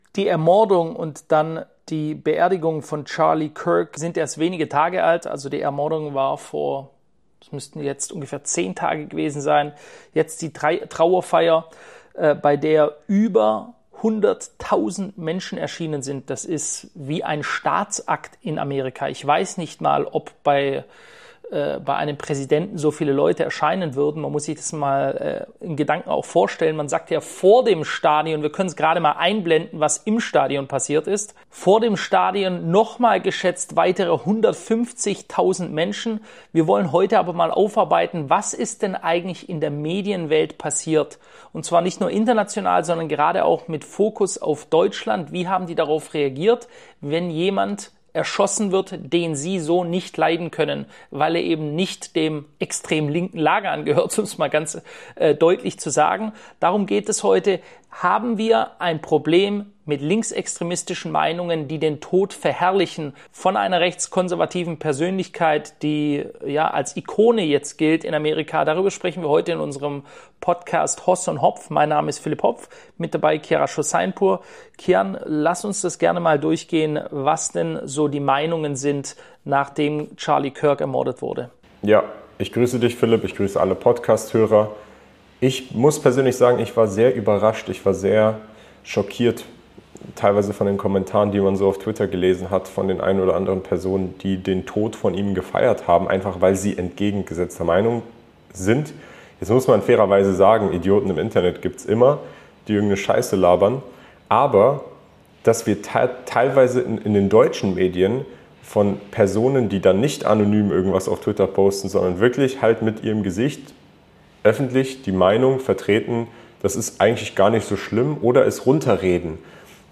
Eine facettenreiche Diskussion über Medienverantwortung, gesellschaftliche Spaltung, internationalen Extremismus und die Zerbrechlichkeit des zivilen Austauschs.